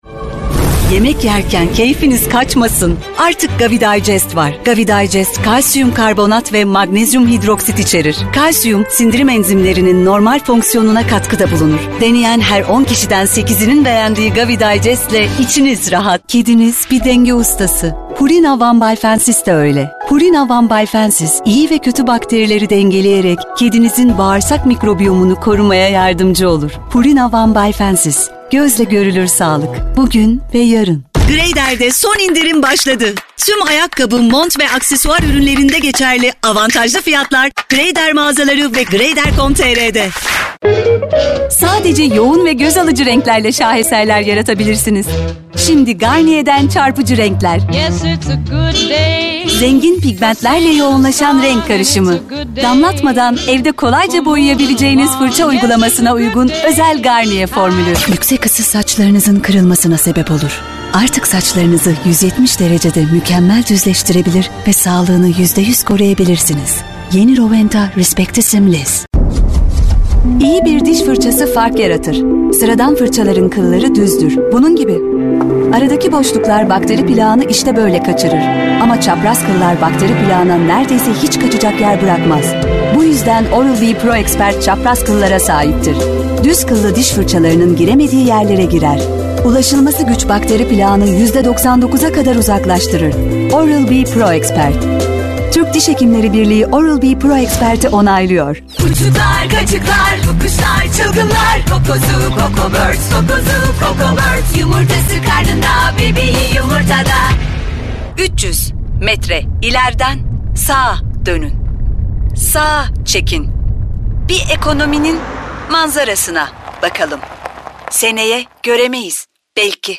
Kadın
Canlı, Eğlenceli, Sakin, Güvenilir, Karakter, Seksi, Animasyon, Şefkatli, Karizmatik, Vokal, Promosyon, Dialekt, Sıcakkanlı, Film Sesi, Tok / Kalın, Dış Ses, Dostane,